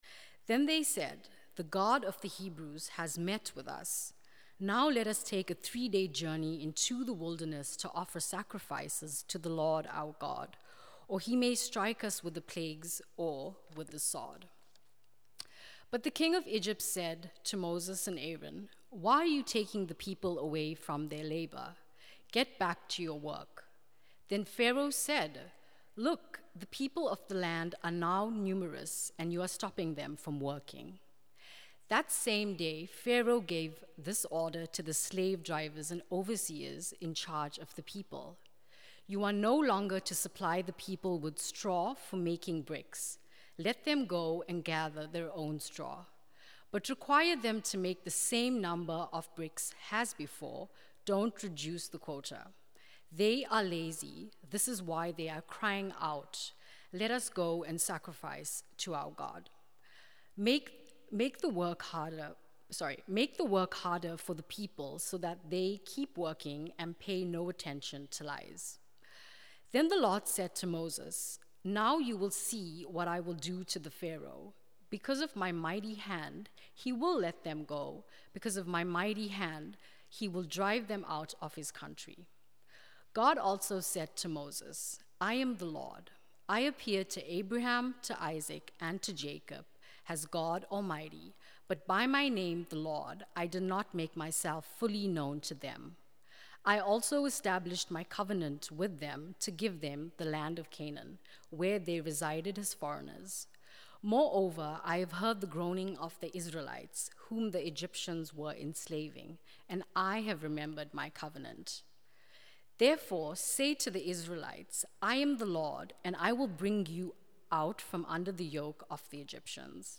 The sermon highlights the difference between false expectations and true hope: God doesn’t always promise immediate relief but always promises certain redemption.